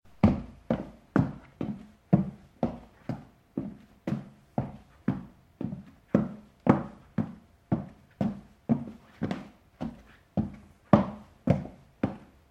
06Shagilestnica2secundy.ogg